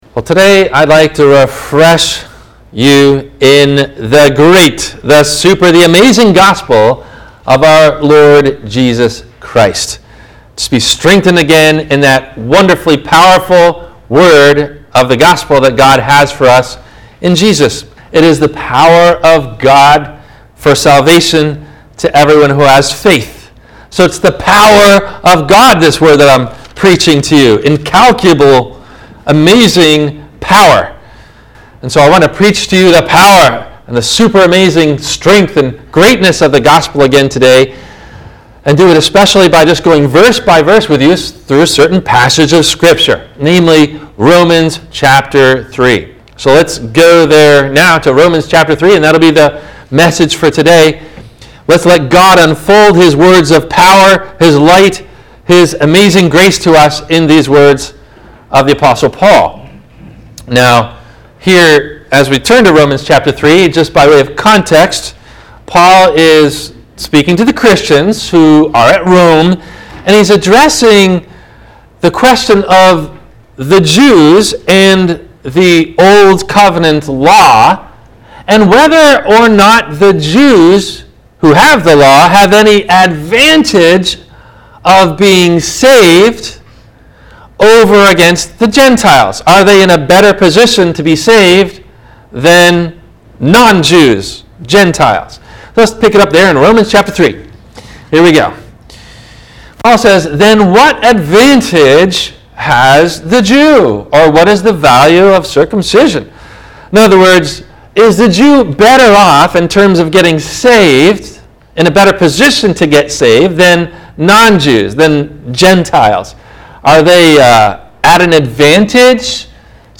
The Gospel That Saves – WMIE Radio Sermon – August 24 2020
No Questions asked before the Radio Message.